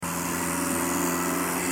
B_SKIDOO_02.mp3